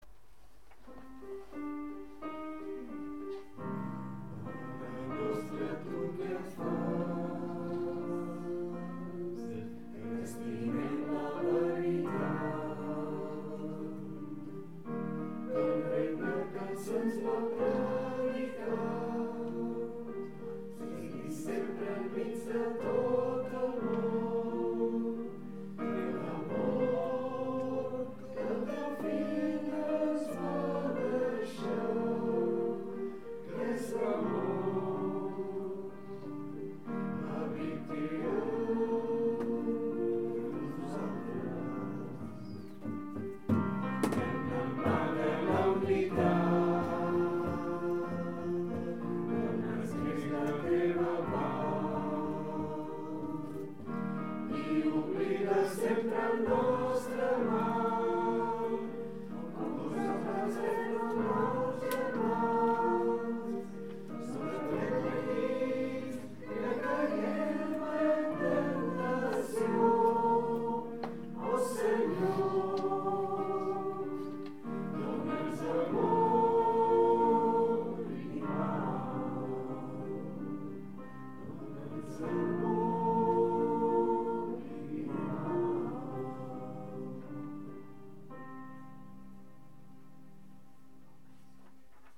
Pregària de Taizé
Capella dels Salesians - Diumenge 25 de maig de 2014